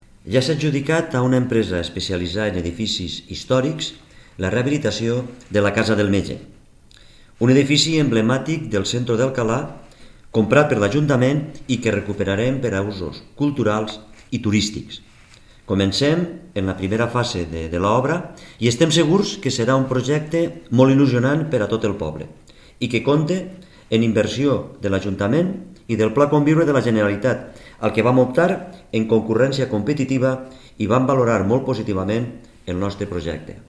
Francisco Juan, alcalde